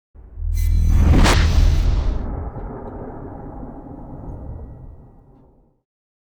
engine_warp_002.wav